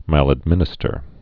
(măləd-mĭnĭ-stər)